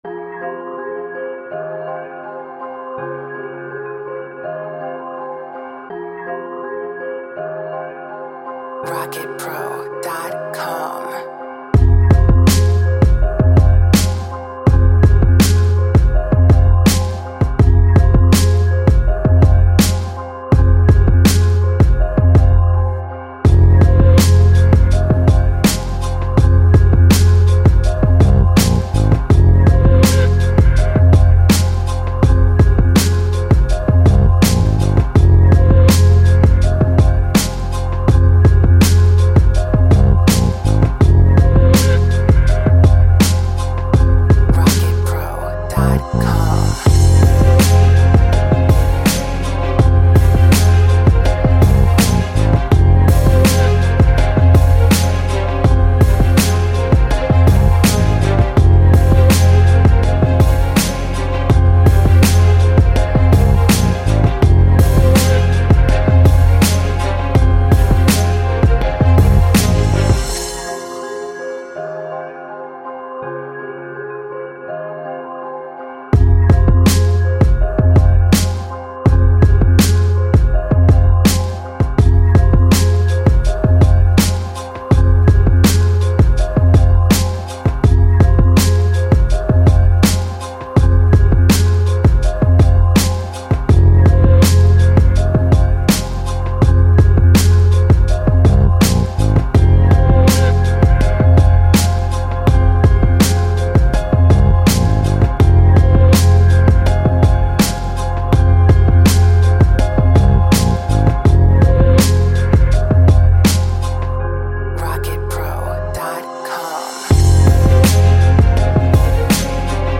Hard hittin beat with some sick drums and melody.
91 BPM.